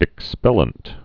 (ĭk-spĕlənt)